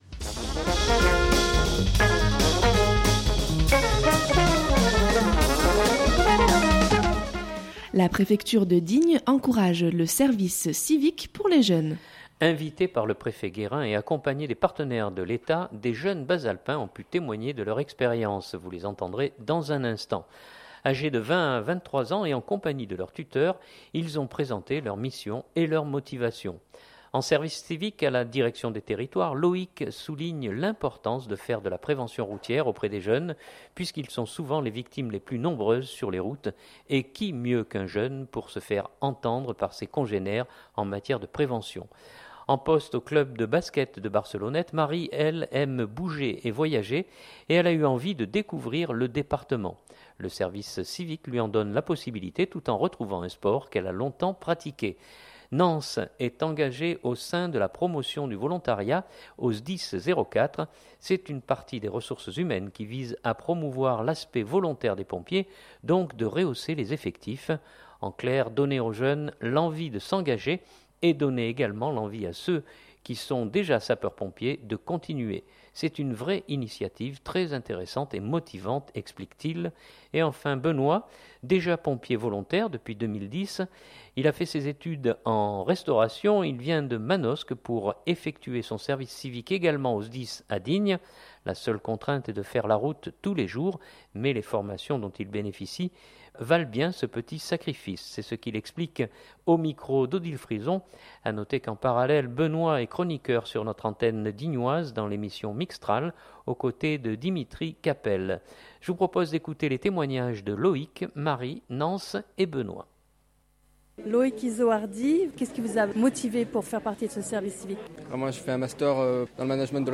Invités par le Préfet Guérin et accompagnés des partenaires de l’Etat, des jeunes bas-alpins ont pu témoigner de leur expérience.